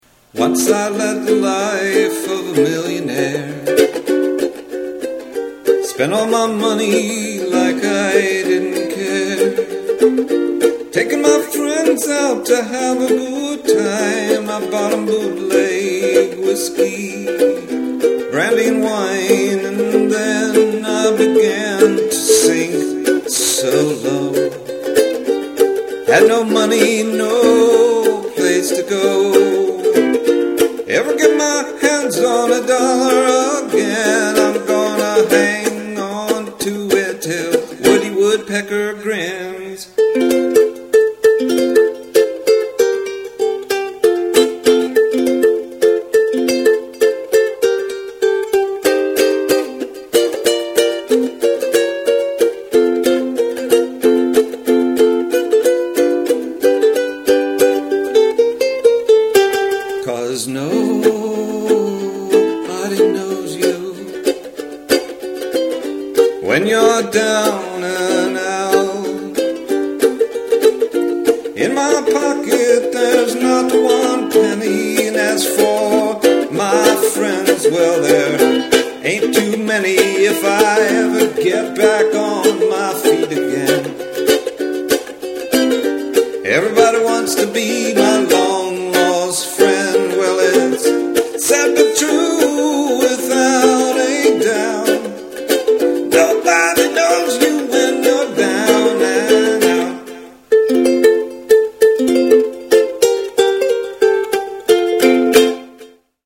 Ukulele Soiree